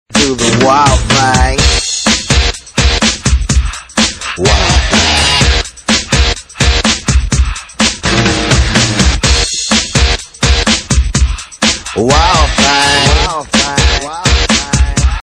Rock Ringtones